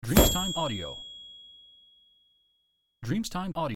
Finocchio di periodo magico con il carillon
• SFX